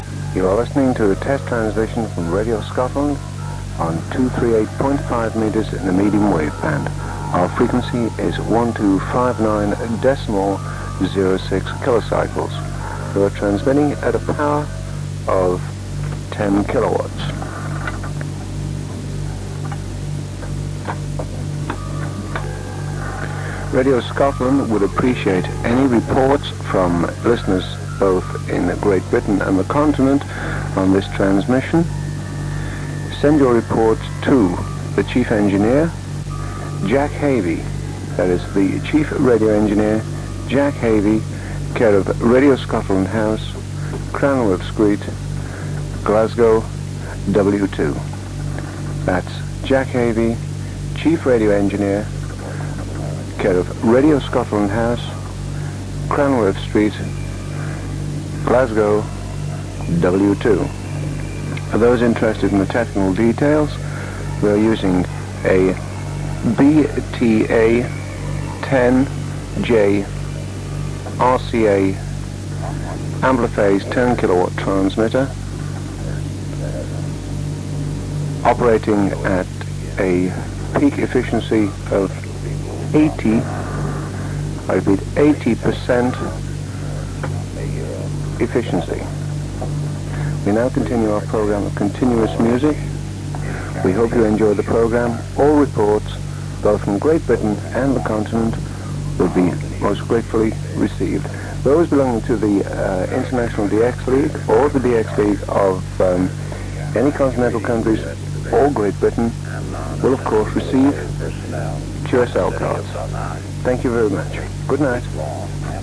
click to hear audio An engineering test transmission for Radio Scotland on the new frequency of 1259kHz, 9th April 1966 (duration 1 minute 55 seconds)